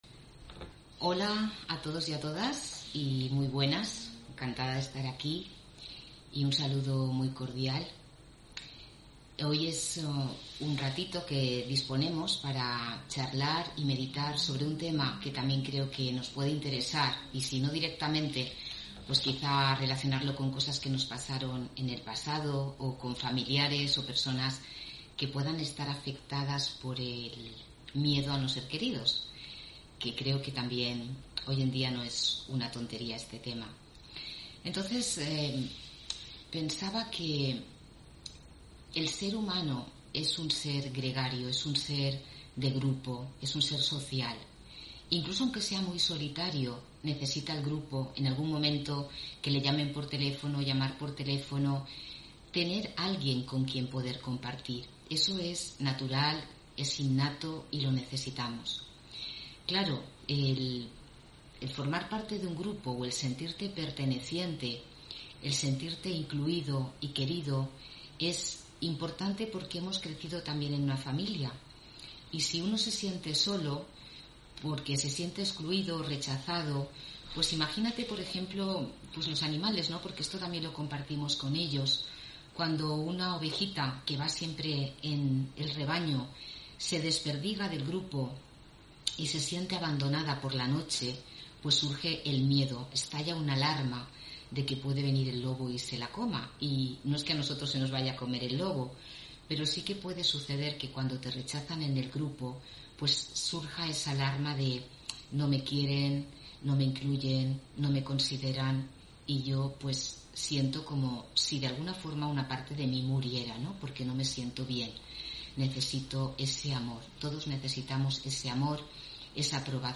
Meditación Raja Yoga y charla: Miedo a no ser querido (17 Julio 2021) On-line desde Valencia